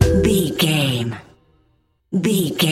Aeolian/Minor
strings
bass guitar
acoustic guitar
flute
percussion
silly
circus
goofy
comical
cheerful
perky
Light hearted
quirky